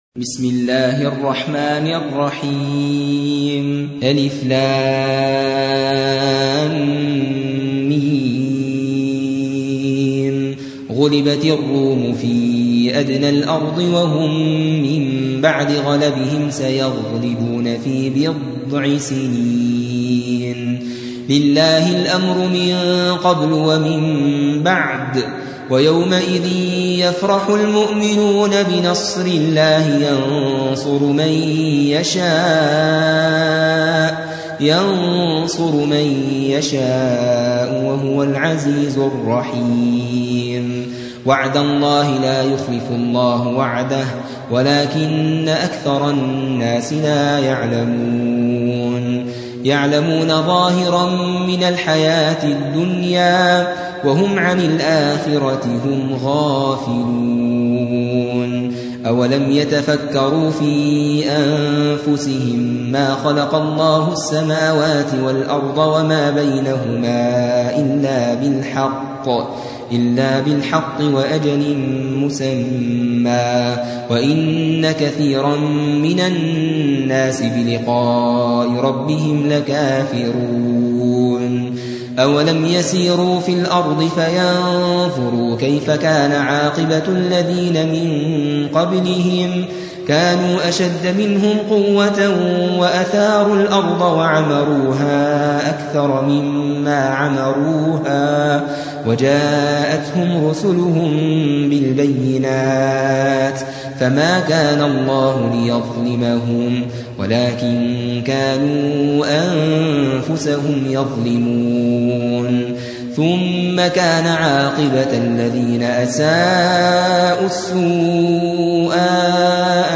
30. سورة الروم / القارئ